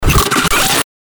FX-1879-BREAKER
FX-1879-BREAKER.mp3